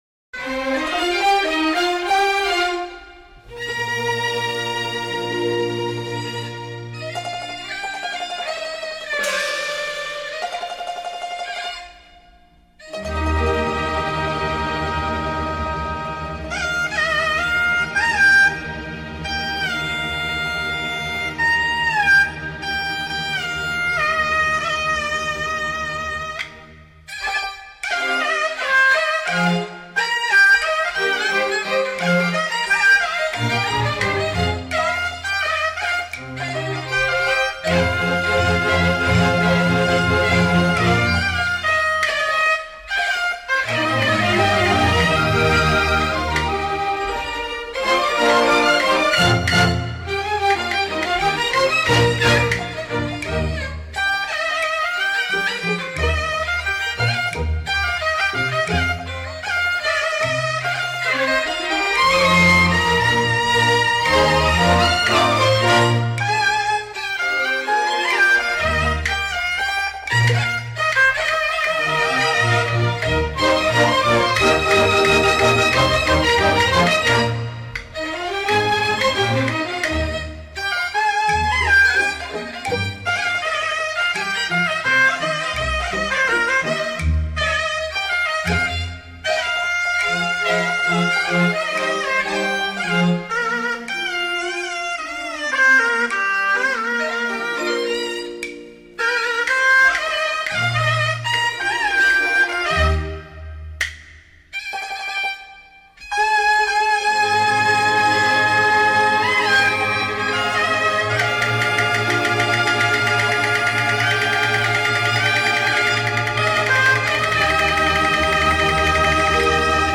全部曲目充分结合了管弦乐器和民族乐器，使得音乐本身即更加立体感，又不失民族本色
音乐类型: 民乐
录音地点：中国·上海